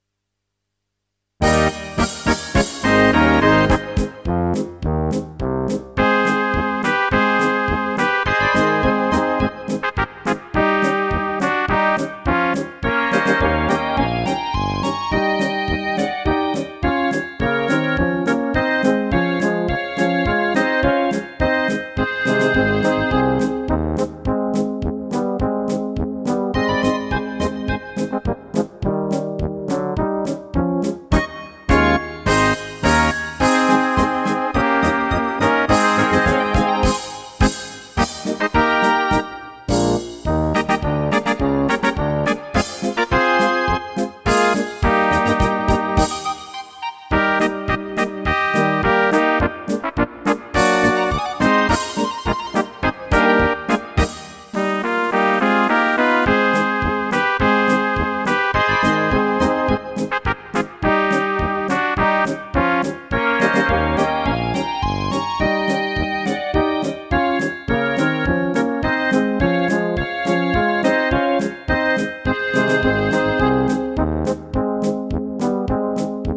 Rubrika: Národní, lidové, dechovka
- polka